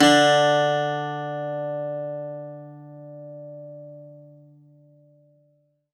52-str01-bouz-d2.wav